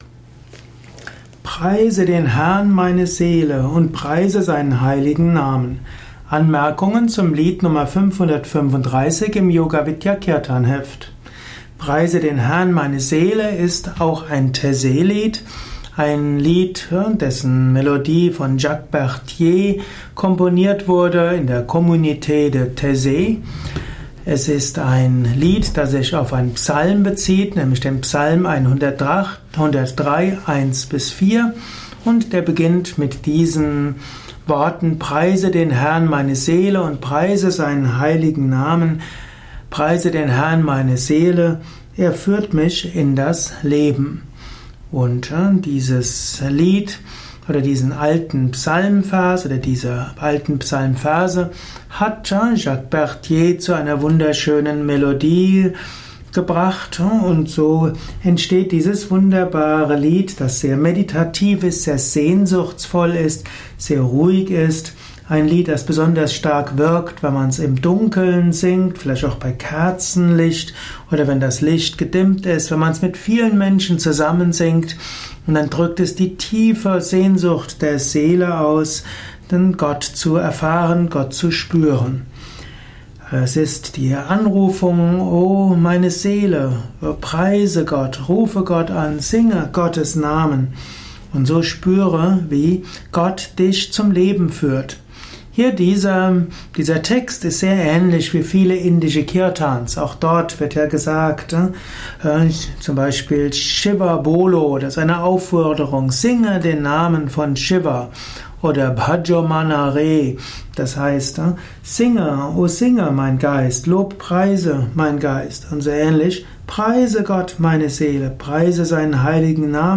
Audio mp3 Erläuterungen